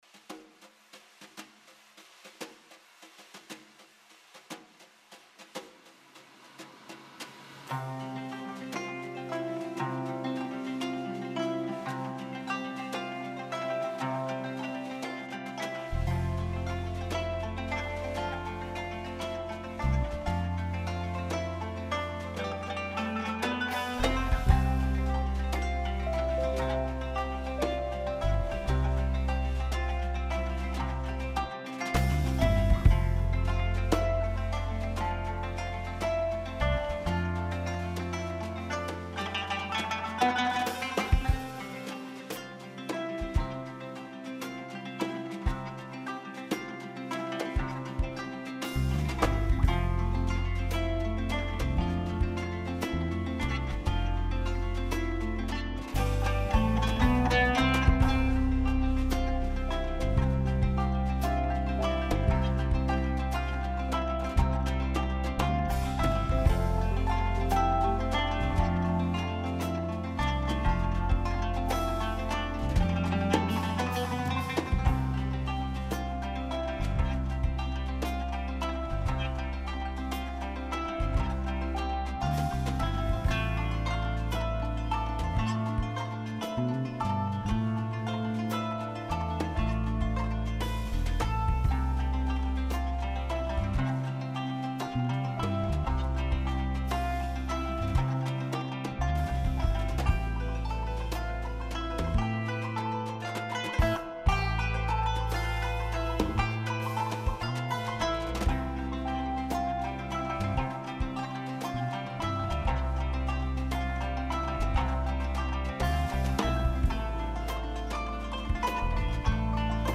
Η συνέντευξη